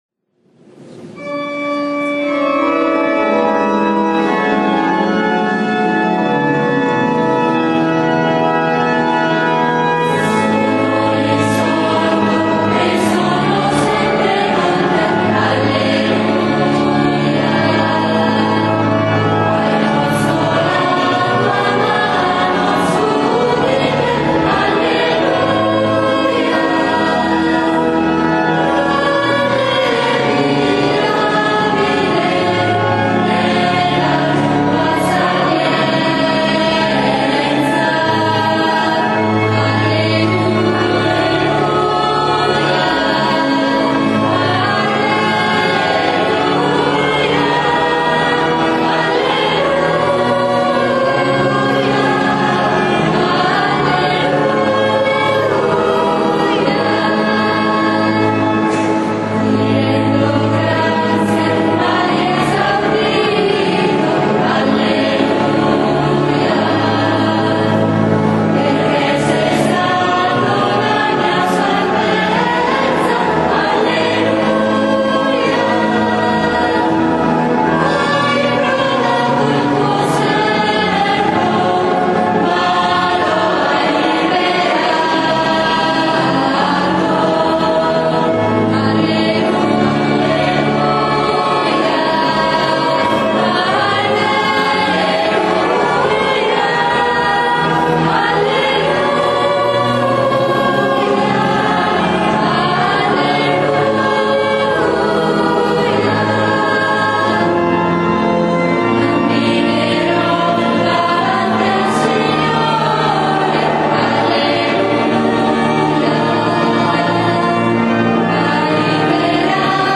PASQUA DI RESURREZIONE
canti: